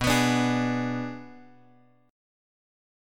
Bm6 chord {x 2 x 4 3 4} chord
B-Minor 6th-B-x,2,x,4,3,4.m4a